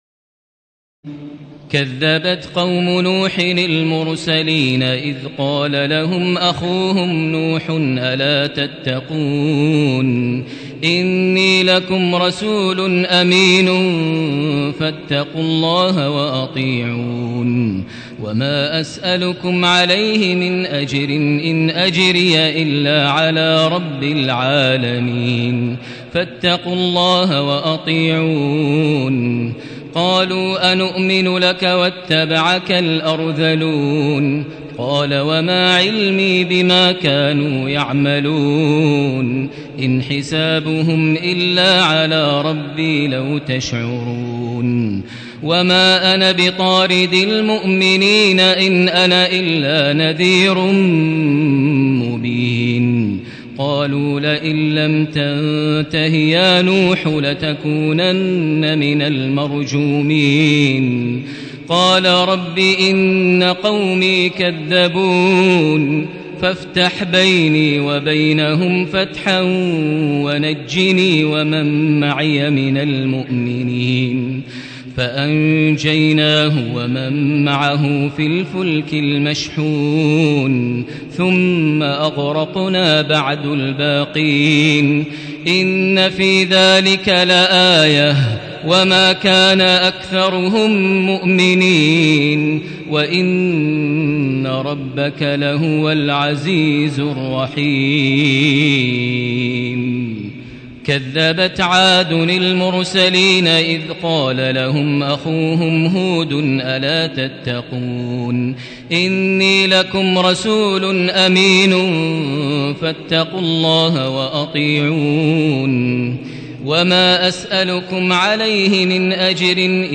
تراويح الليلة الثامنة عشر رمضان 1437هـ من سورتي الشعراء (105-227) والنمل (1-58) Taraweeh 18 st night Ramadan 1437H from Surah Ash-Shu'araa and An-Naml > تراويح الحرم المكي عام 1437 🕋 > التراويح - تلاوات الحرمين